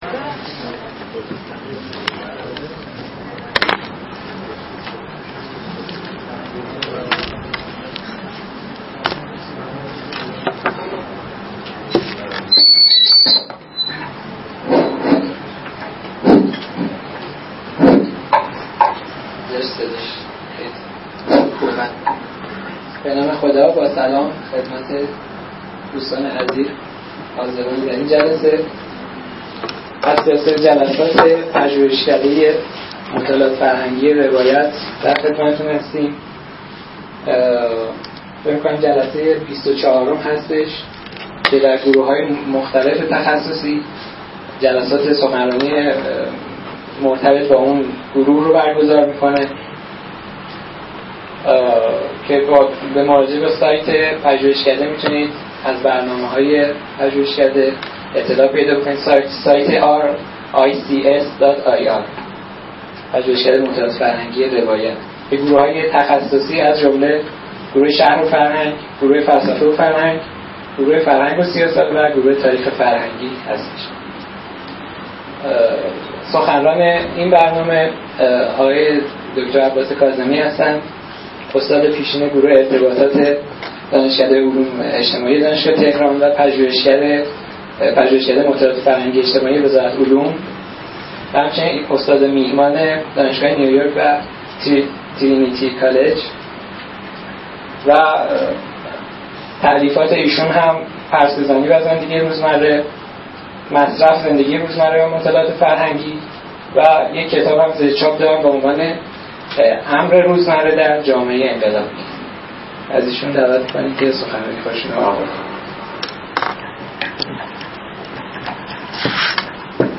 این نشست به همت پژوهشکده مطالعات فرهنگی روایت ۶ امرداد ۹۴ در این مرکز برگزار شد.